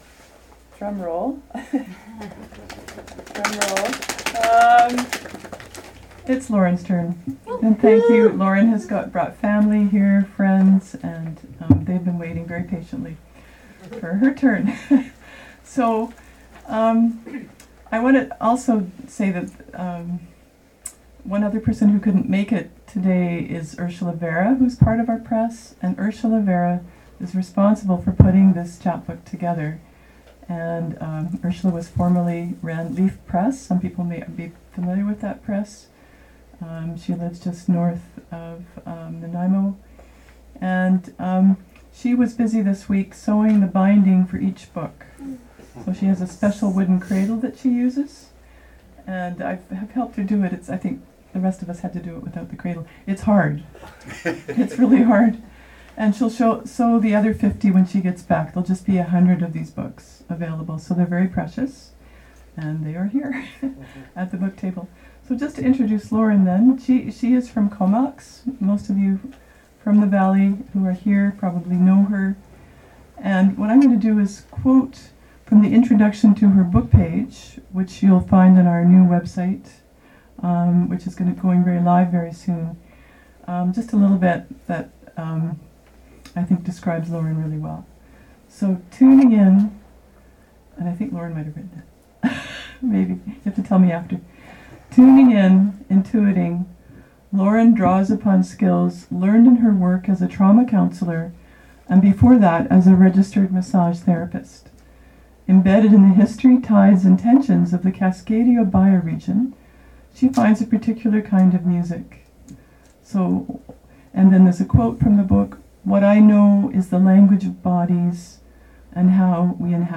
Pictures and updates from CPL and Cascadia 2050's trip to Cumberland, BC for the Rain Shadow Poetry Festival.
reading at Artful: The Gallery